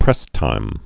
(prĕstīm)